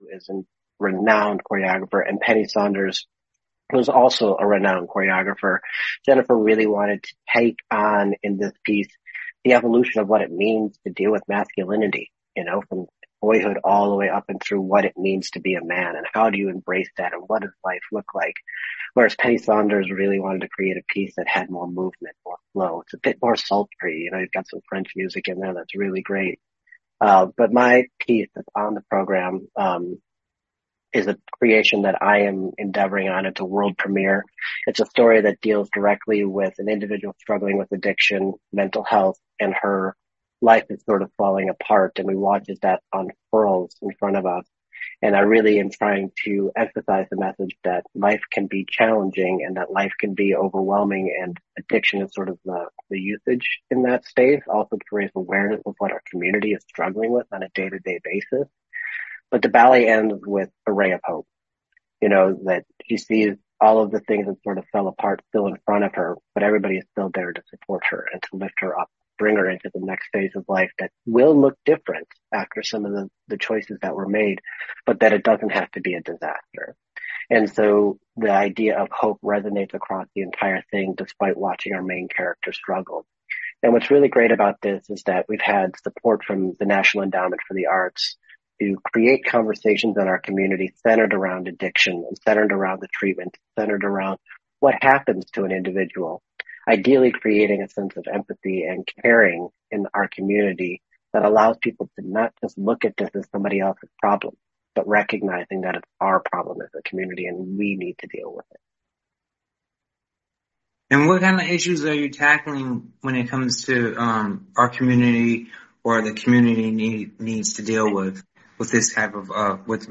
a no-nonsense conversation